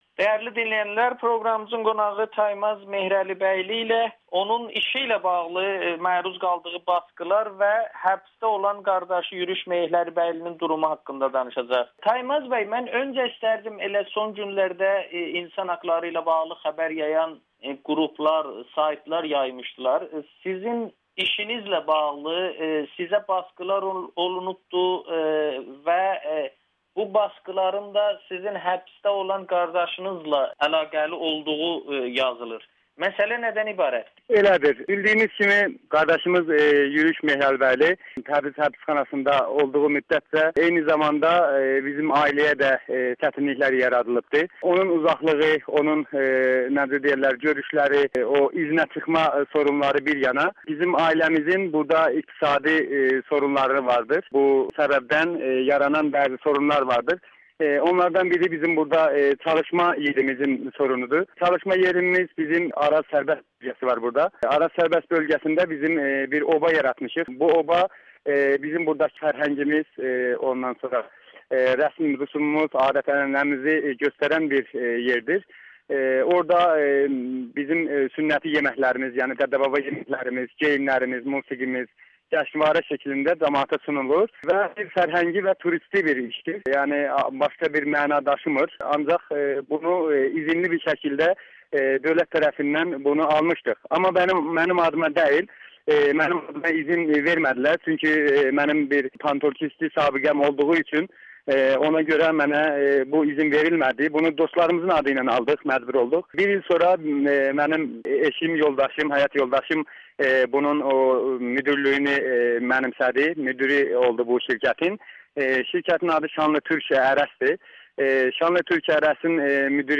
Audio-Müsahibə